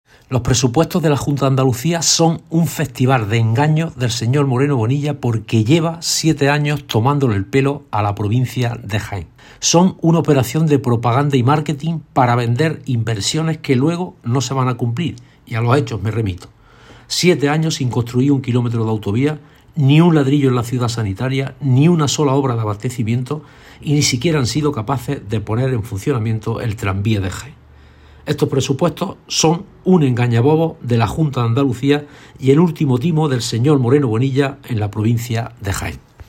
Cortes de sonido # Jacinto Viedma